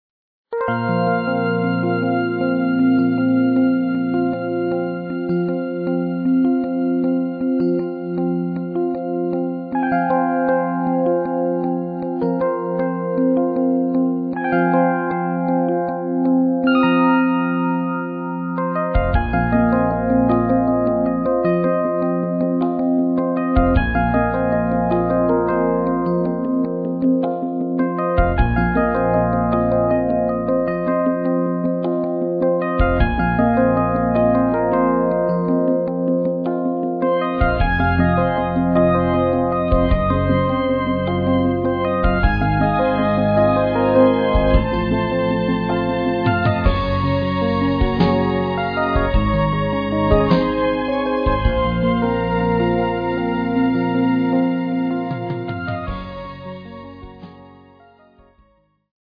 Electro-acoustic